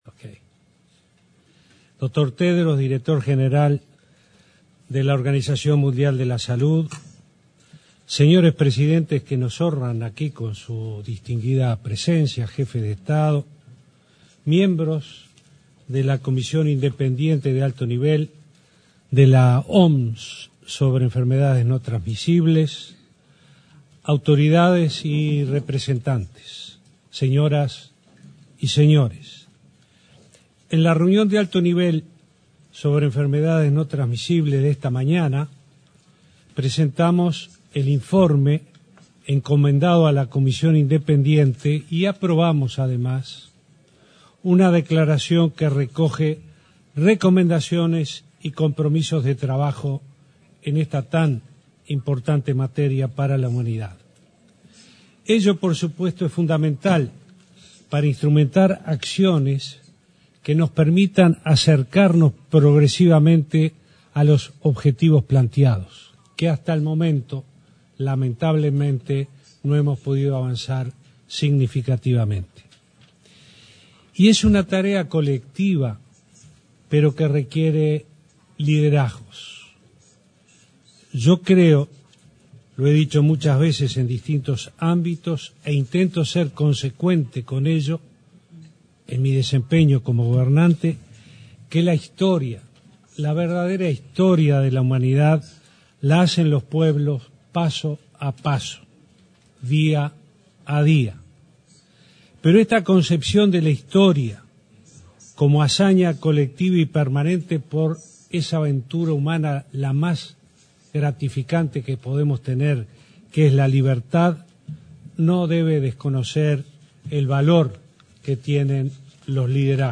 “Nuestro liderazgo en materia de política para prevenir las enfermedades no transmisibles radica en una estrategia país y en un fuerte involucramiento ciudadano”, sostuvo el presidente Vázquez en una reunión de la Comisión Independiente de Alto Nivel de la OMS. El mandatario habló del caso uruguayo y de la normativa legal para regular los mercados del tabaco, alcohol y cannabis, además del rotulado de alimentos.